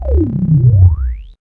2405L SUBSWP.wav